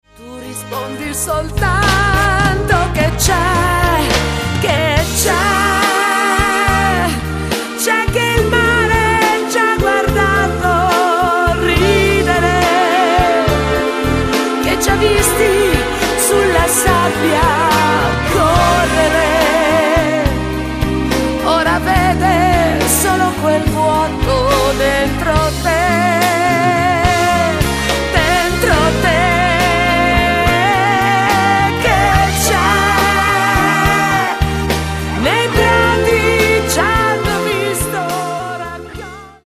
TERZINATO  (4.02)